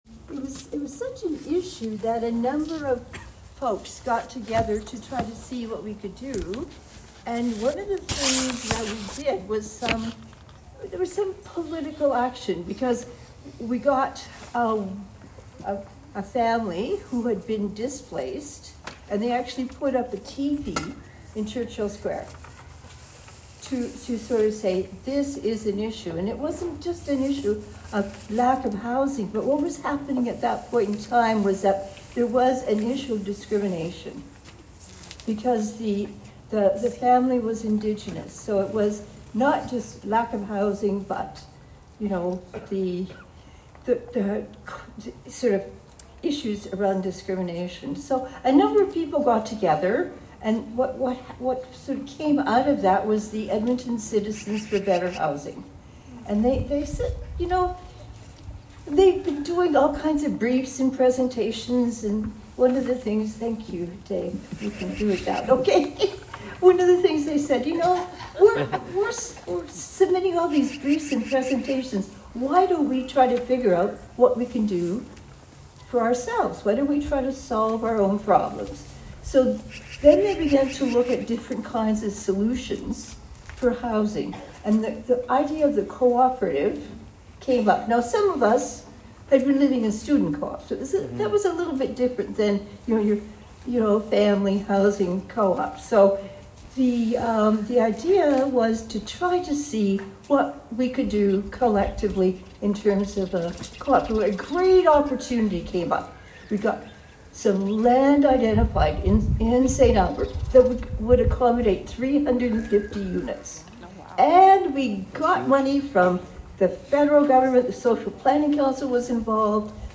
2024 Presentation